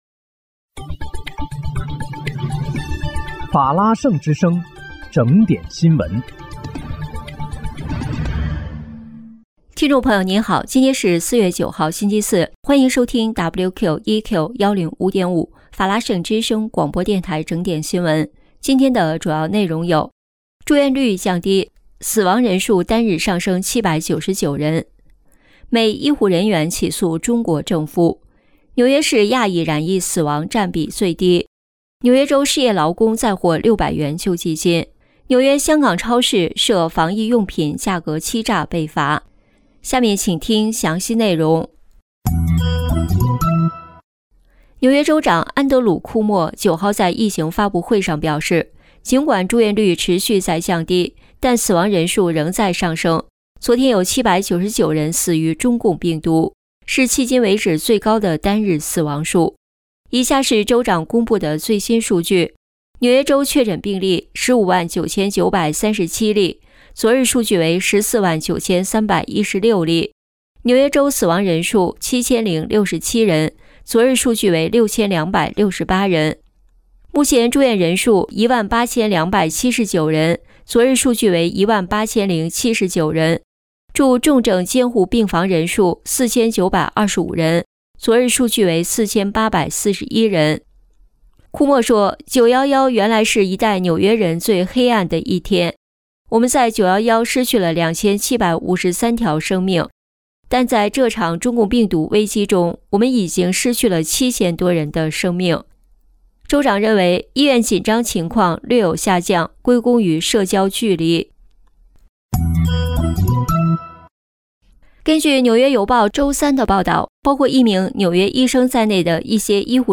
4月9日（星期四）纽约整点新闻